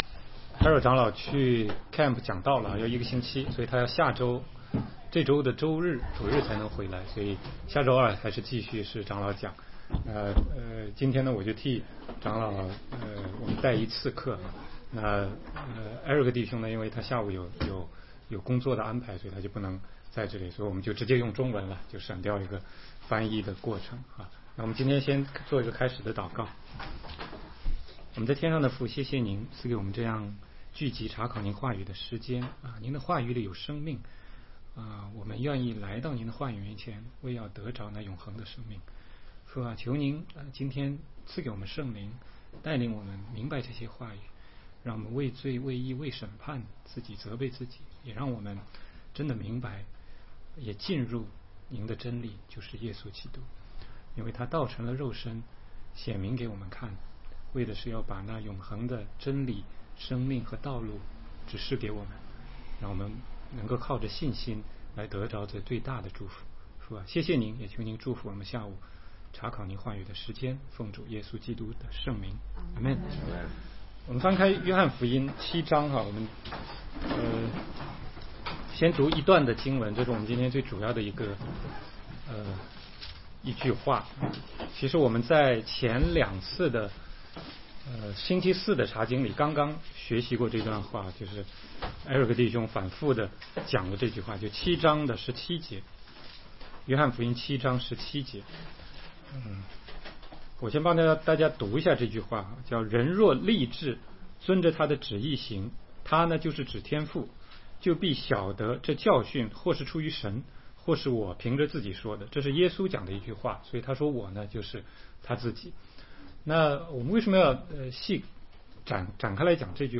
16街讲道录音 - 立志遵着神的旨意行
全中文查经